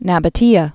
(năbə-tēə)